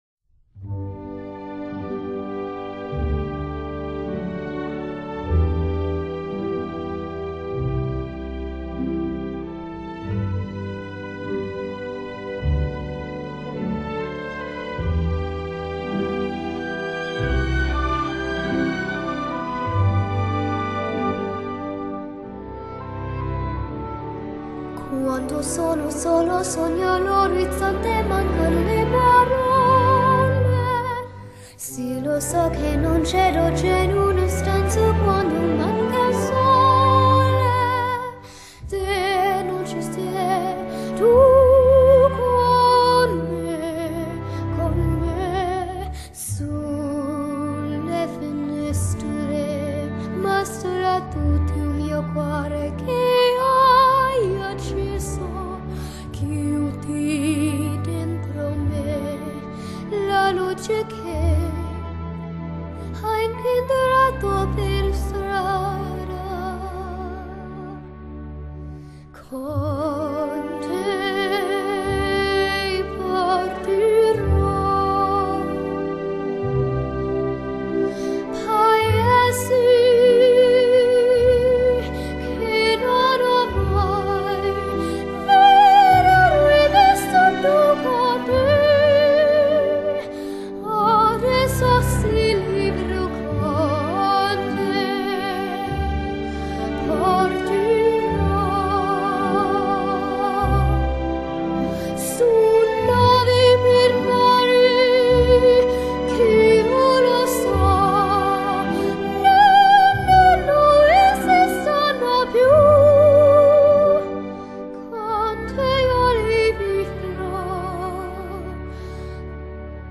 Easy Listening, Vocal